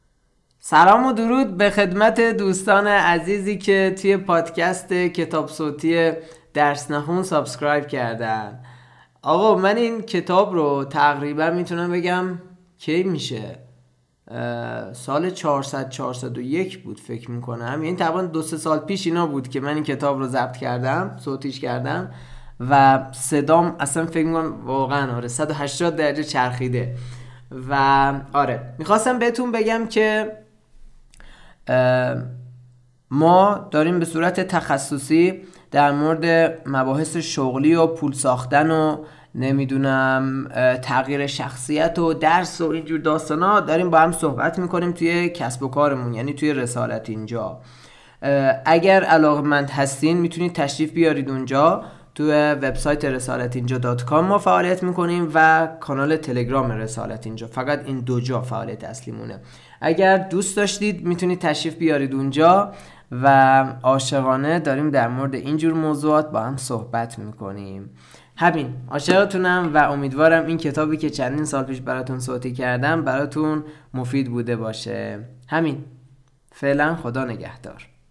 کتاب صوتی درس نخون